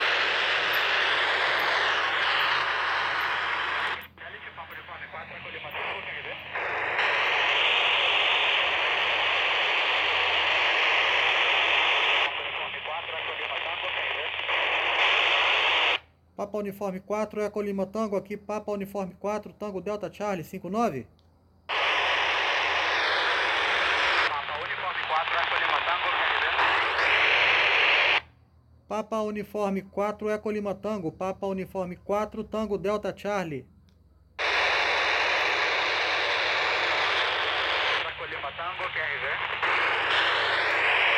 had a brief contact using the FM Transponder of AO-123